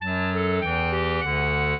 clarinet
minuet3-7.wav